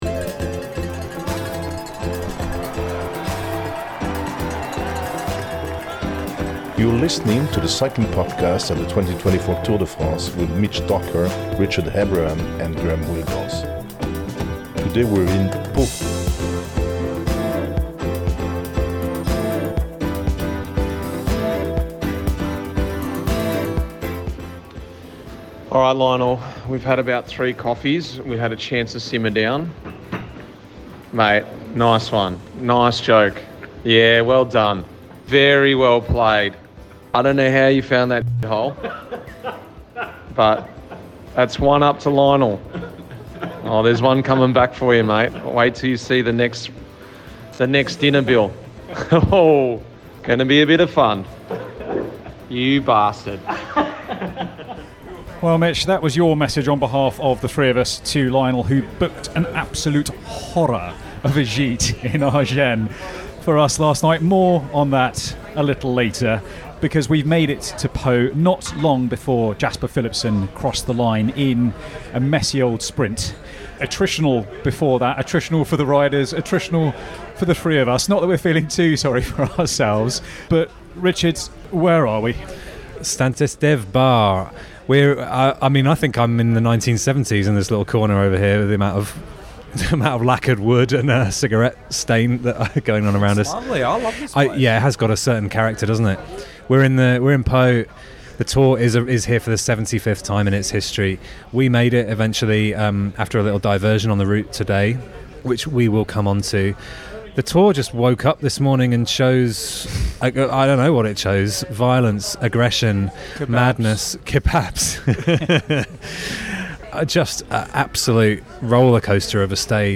With a new line-up for the 2024 Tour, there’ll be the familiar mix of lively discussion, race analysis, interviews from Outside the Team Bus and some French flavour.